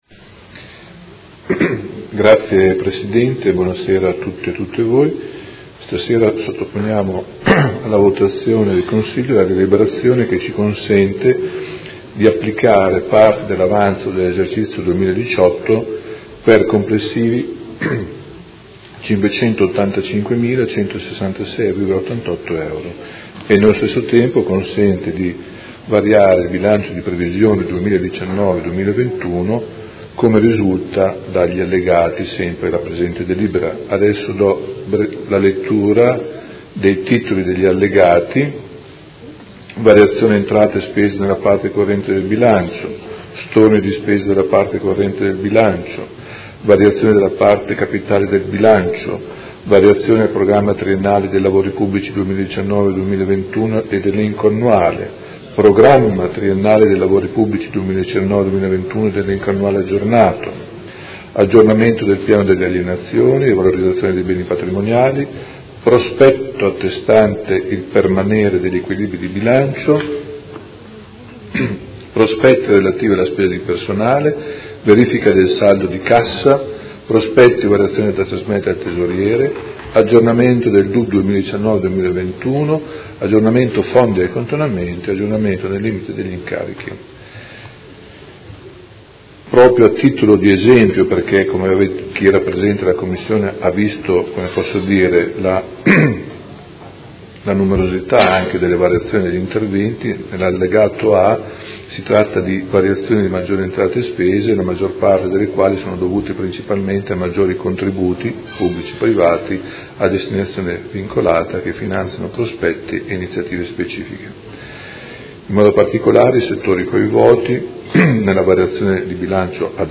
Seduta del 26/09/2019. Proposta di deliberazione: Bilancio 2019 - 2021, Programma Triennale dei Lavori Pubblici 2019-2021 - Programma Biennale degli acquisti, forniture e servizi 2019-2020 - Variazione di Bilancio n. 6